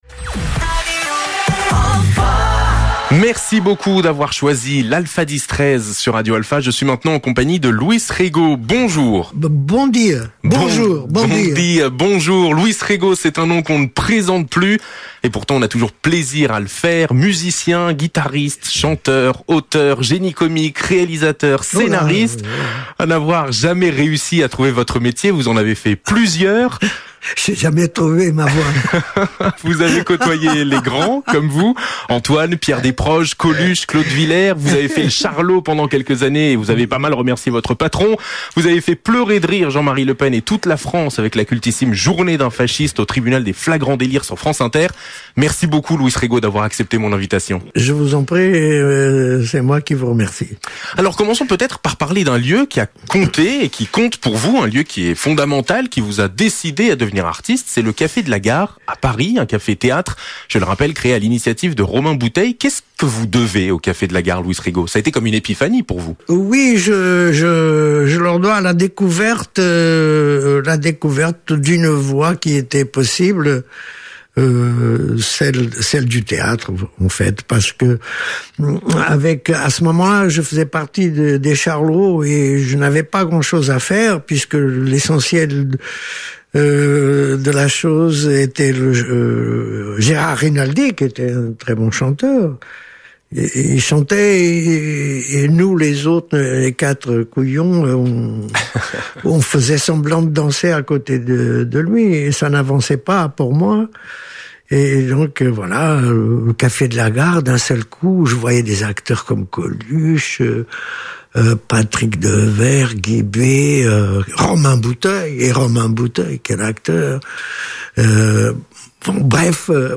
Luis Rego dans les studios de Radio Alfa C’est un témoignage précieux.
Interview-Luis-Rego-1.mp3